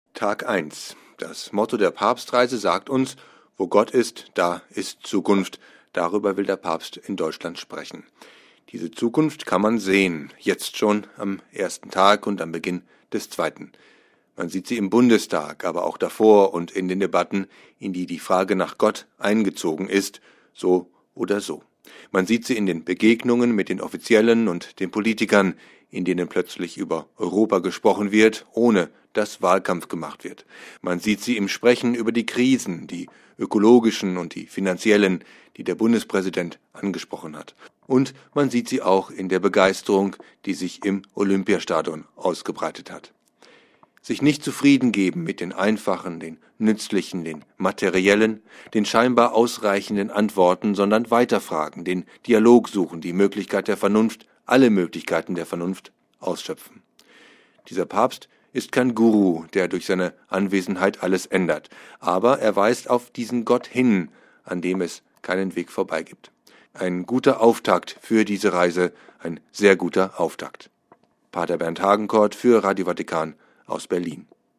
So war Tag 1 der Papstreise: Ein Kommentar